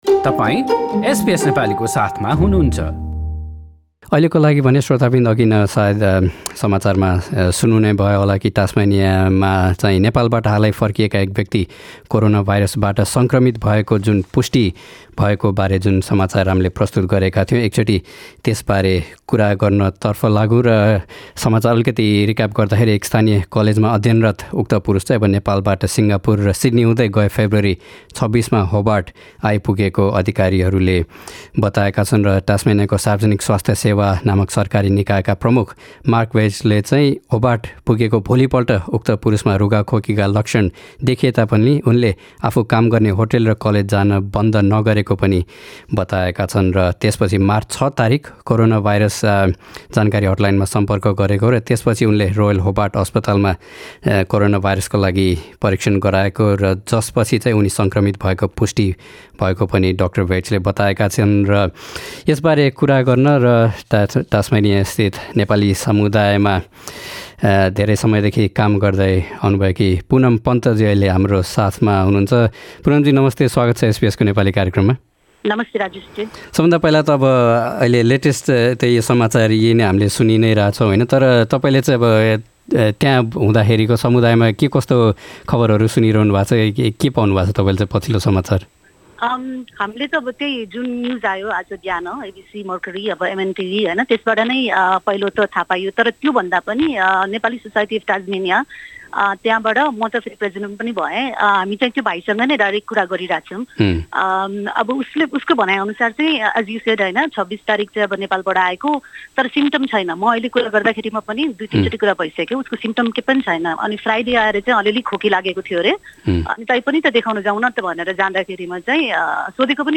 हाम्रो कुराकानी